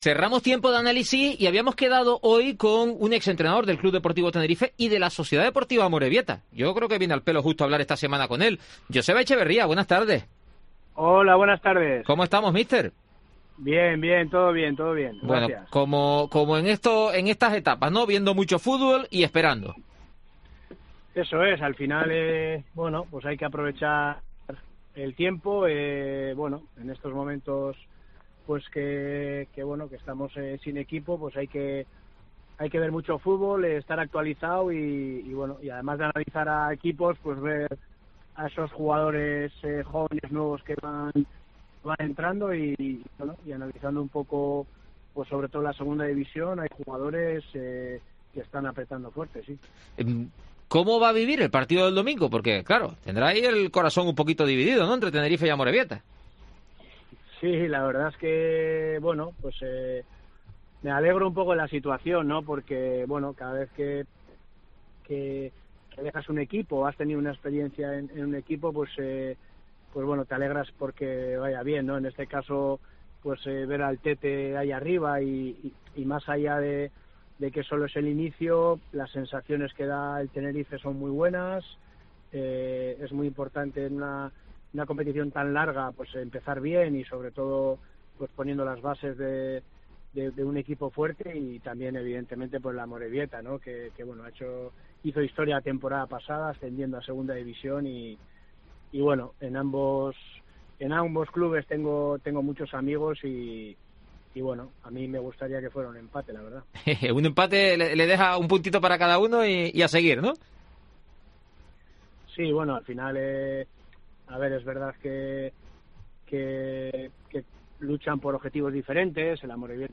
Etrevista a Joseba Etxeberria